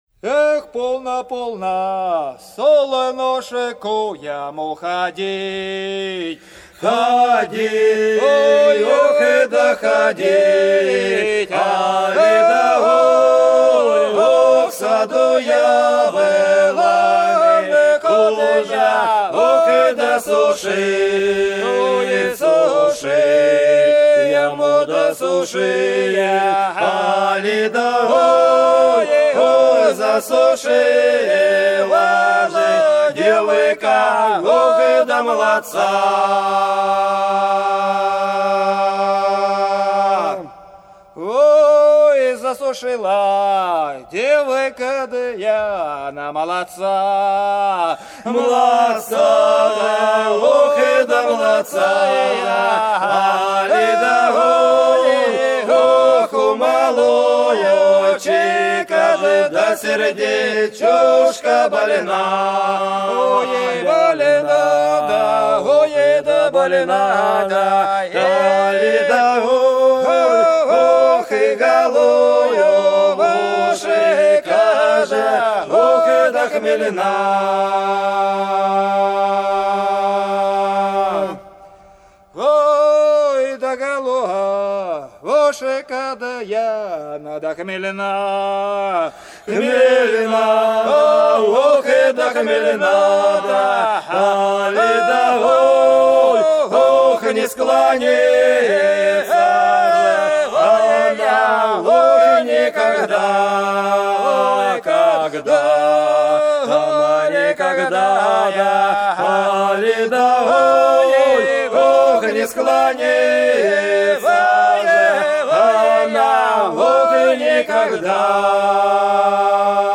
Долина была широкая (Поют народные исполнители села Нижняя Покровка Белгородской области) Полно солнышку ходить - протяжная